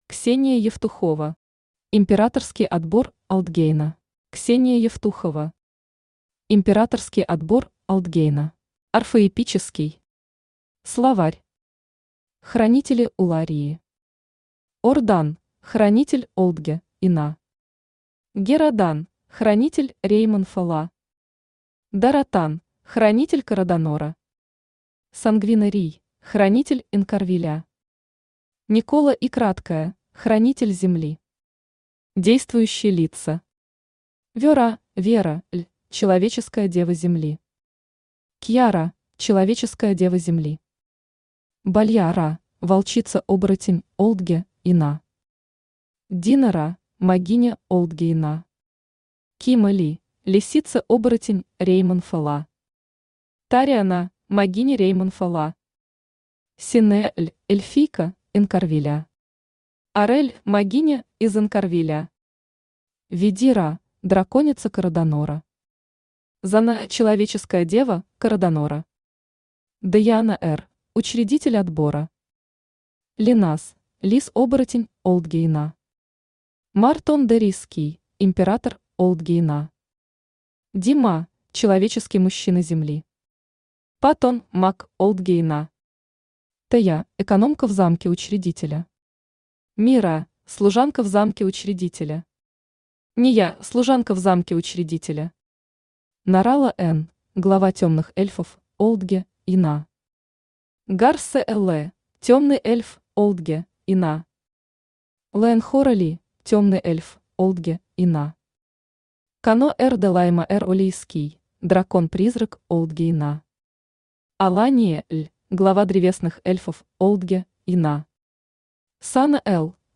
Аудиокнига Императорский отбор Олтгейна | Библиотека аудиокниг
Aудиокнига Императорский отбор Олтгейна Автор Ксения Андреевна Евтухова Читает аудиокнигу Авточтец ЛитРес.